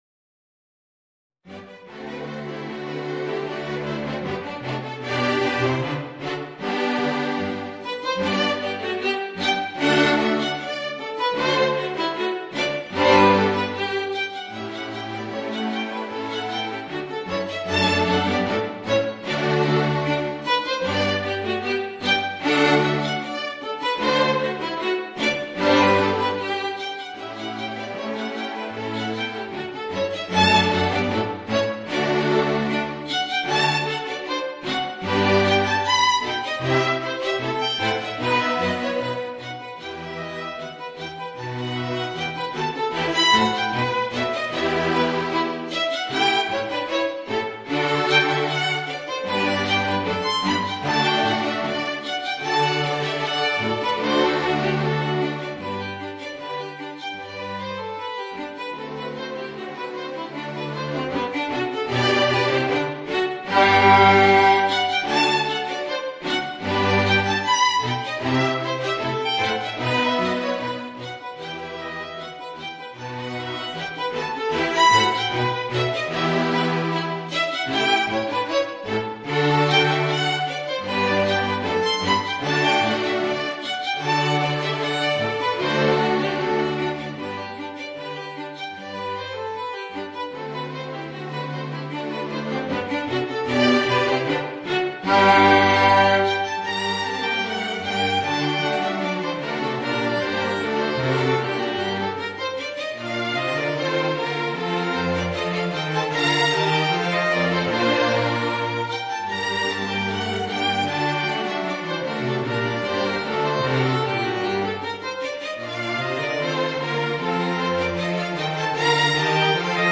Voicing: Clarinet and String Quartet